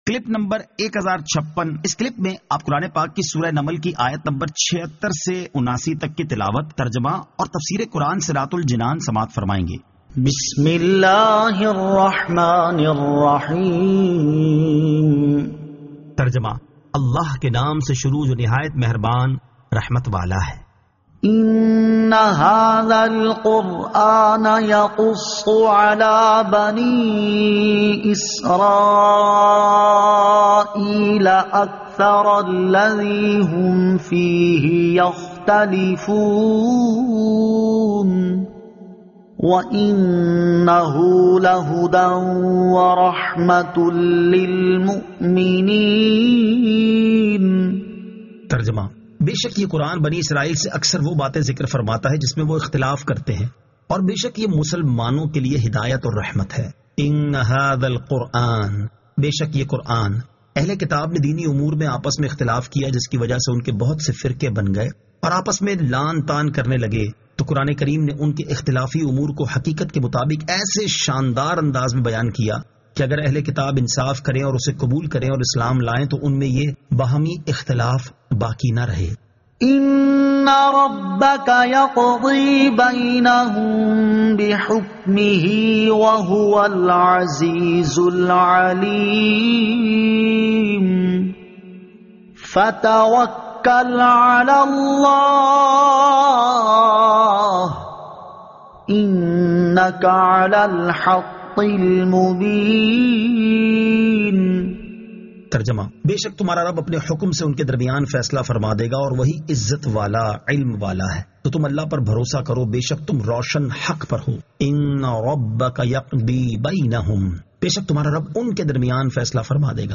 Surah An-Naml 76 To 79 Tilawat , Tarjama , Tafseer